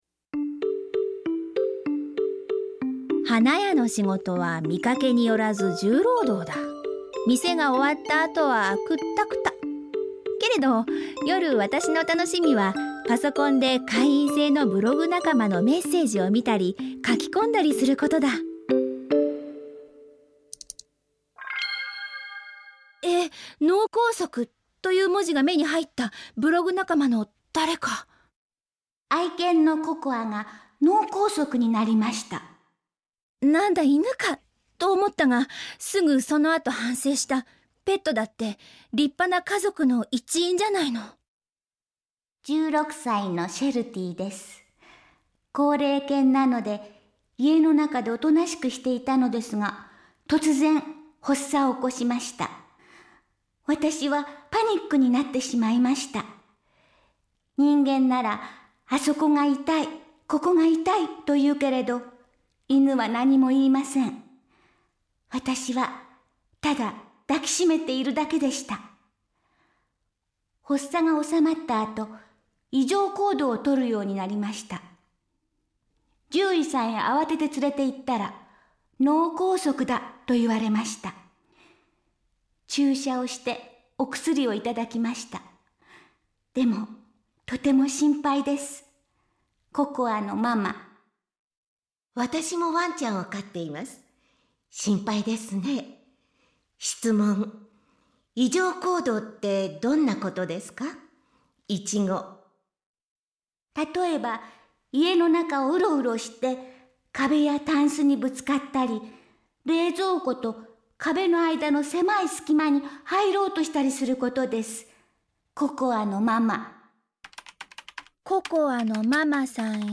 ●ラジオドラマ「花ものがたり」
・女（中年）
・男（中年）